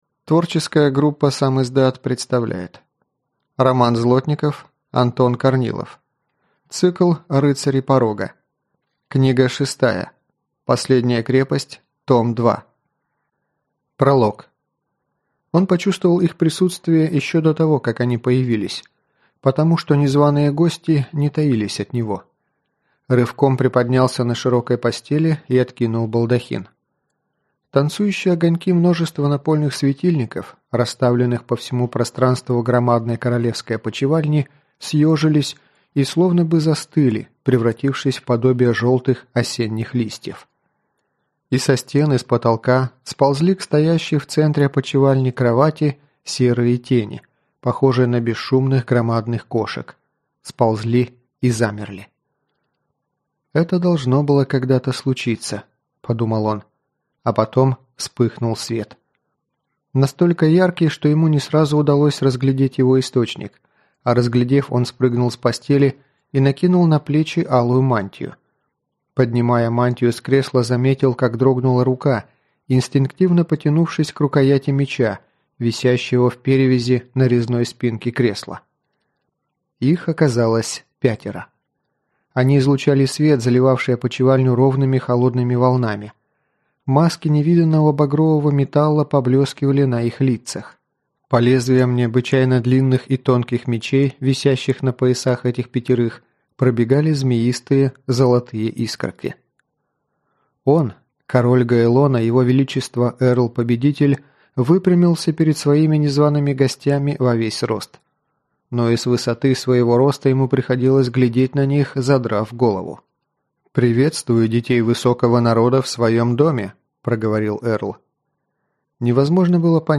Аудиокнига Последняя крепость. Том 2 | Библиотека аудиокниг